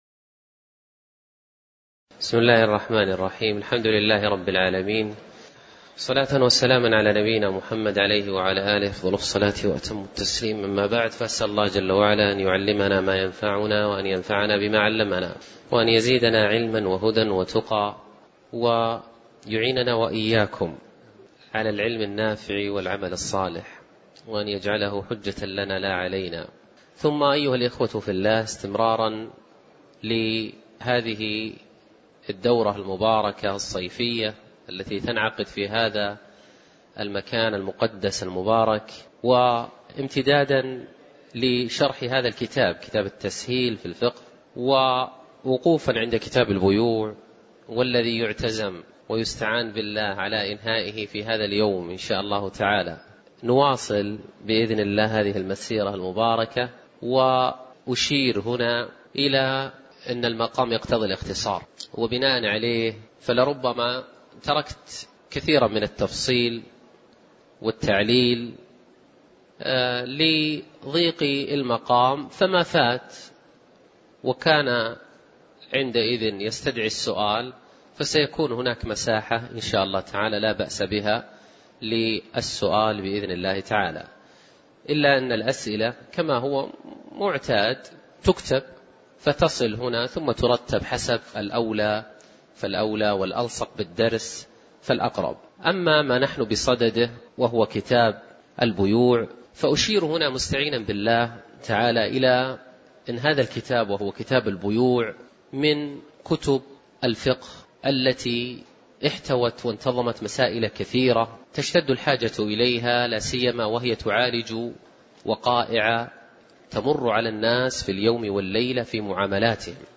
تاريخ النشر ١٩ شوال ١٤٣٩ هـ المكان: المسجد النبوي الشيخ